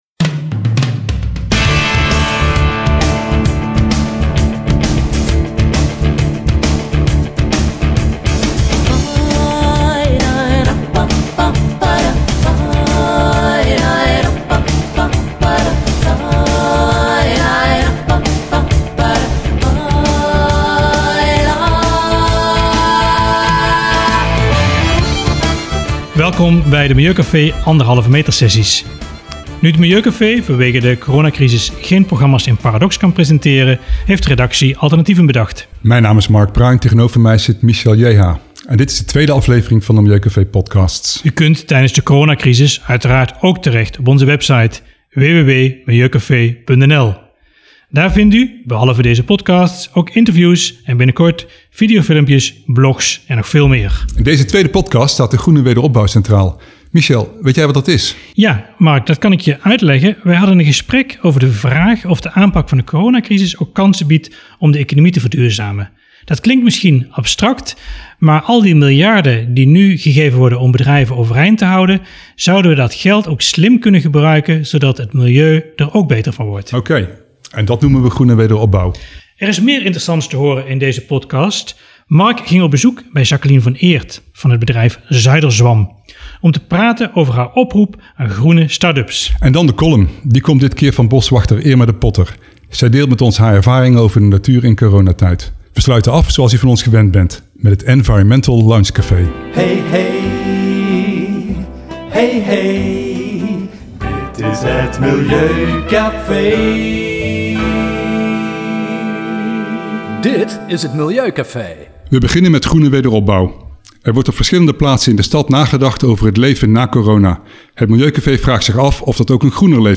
een gesprek